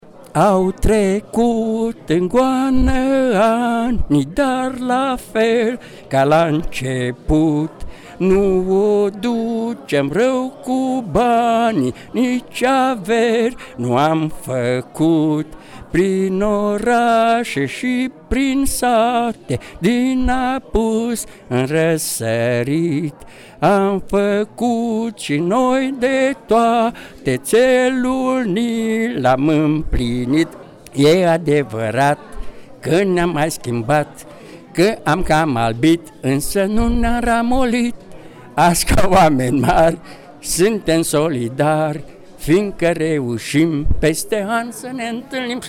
VIDEO și GALERIE FOTO: Sinceritate și bucurie, la revederea de 40 de ani a promoției `79, a Liceului Tehnologic „Grigore C. Moisil”, din Buzău
El a ținut chiar să ne cânte o parte din acest imn.
Imnul-promotiei.mp3